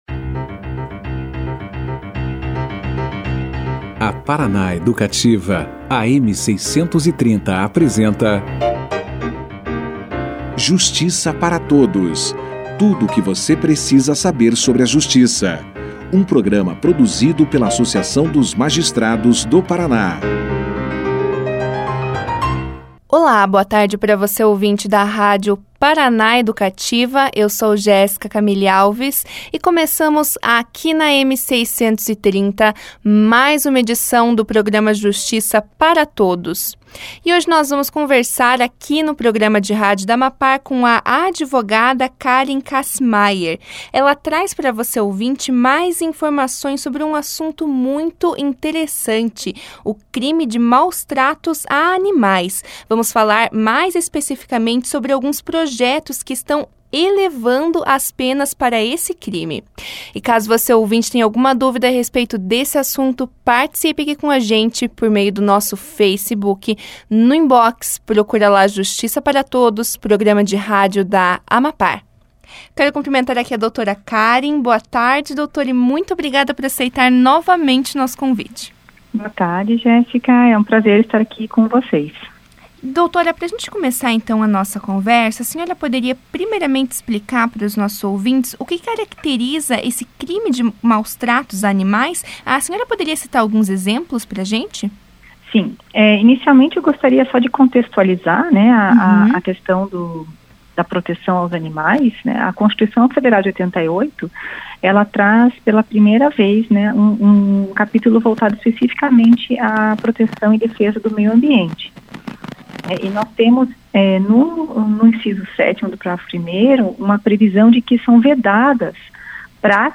O crime de maus- tratos a animais foi o tema debatido no Justiça para Todos, desta terça-feira (19).
Durante a entrevista, a convidada alertou a população com relação a venda e compra de animais domésticos, incentivando a adoção.